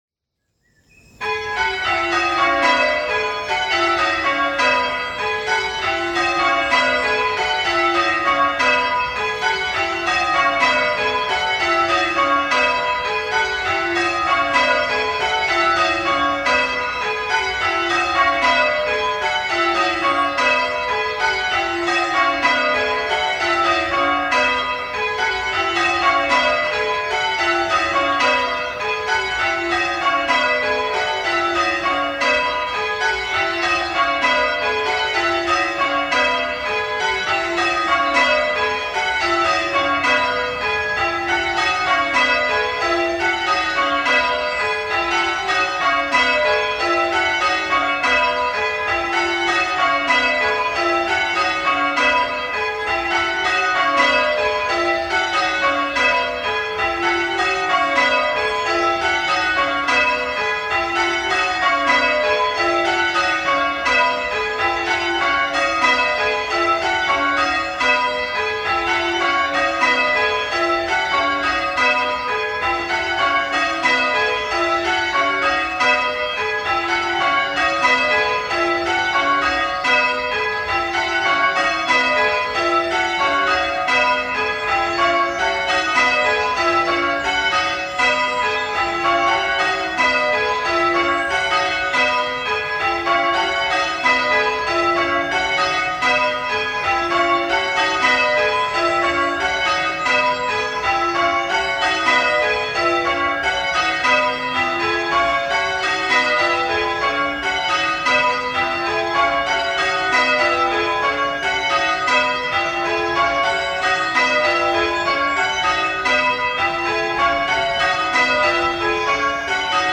6 bells 5-3-15 in B Excellent going order
Peal Records Ground floor ring.
Call Changes, Suffolk Guild 6-bell Striking Competition 2024, Bardwell band, winning entry for the Lester Brett Call-change Trophy, 18th May 2024.